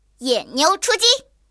野牛出击语音.OGG